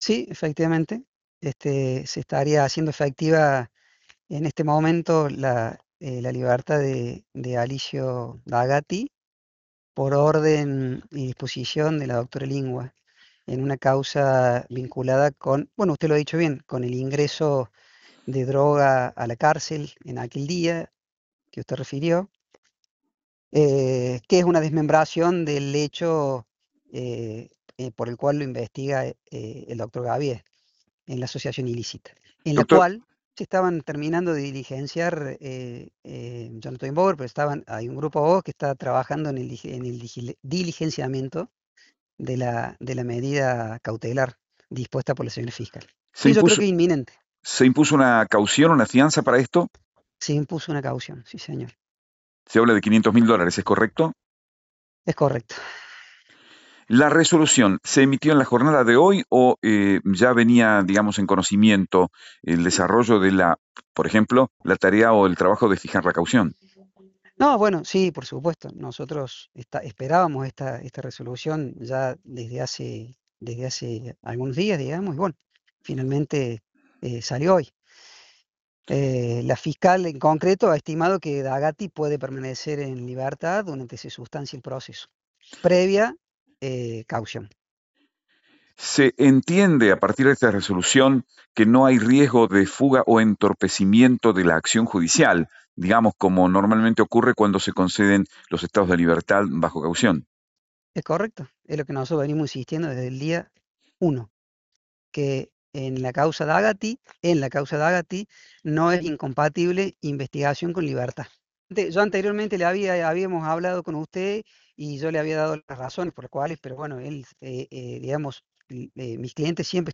Boletín informativo